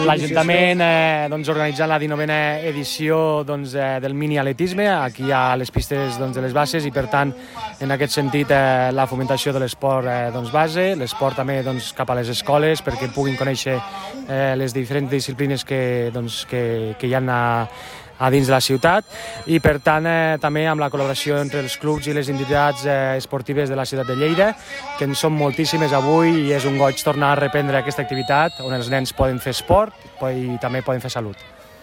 El regidor d’Esports de la Paeria, Ignasi Amor, que ha assistit a la celebració de la jornada acompanyat del també regidor David Melé, s’ha congratulat de poder tornar al camí de la normalitat amb la represa del Miniatletisme, perquè fer esport és també fer salut. Tall de veu Ignasi Amor
tall-de-veu-ignasi-amor